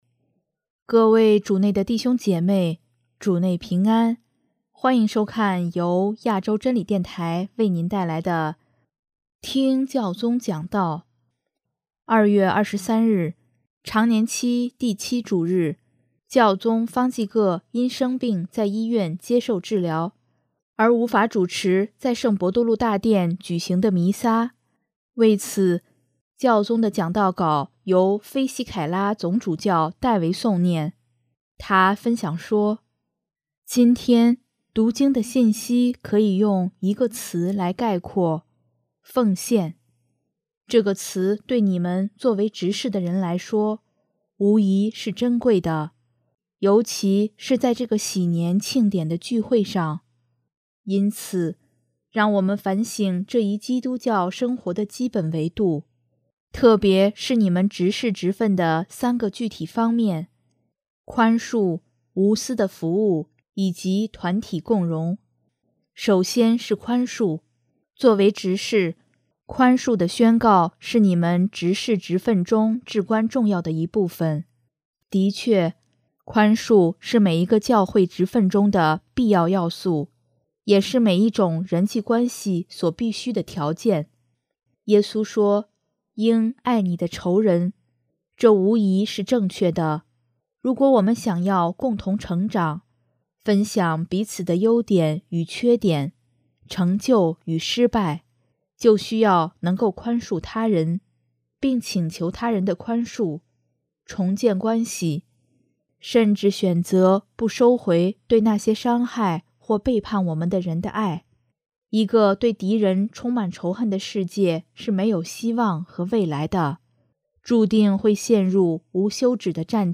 2月23日，常年期第七主日，教宗方济各因生病在医院接受治疗，而无法主持在圣伯多禄大殿举行的弥撒。为此，教宗的讲道稿由菲西凯拉总主教（H.E. MSGR. RINO FISICHELLA）代为诵念。